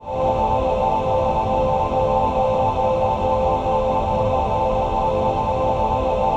VOICEPAD02-LR.wav